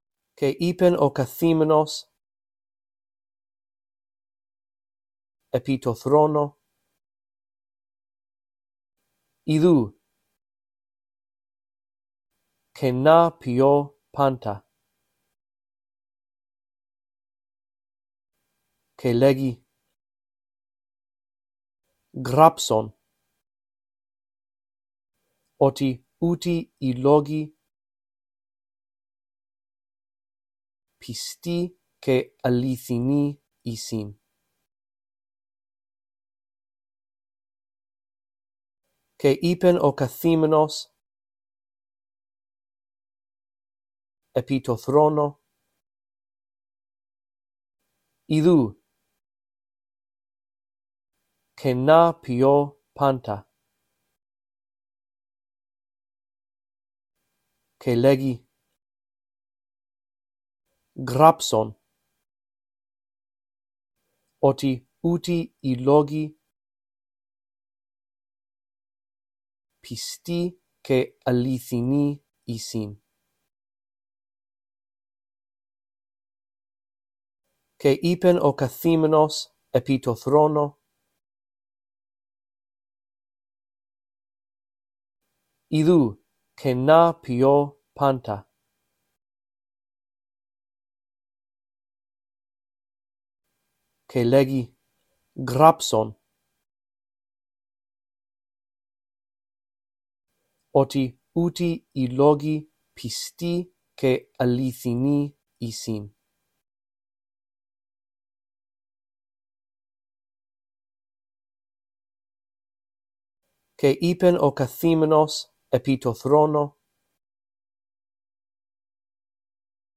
In this audio track, I read through verse 5 a phrase at a time, giving you time to repeat after me. After two run-throughs, the phrases that you are to repeat become longer.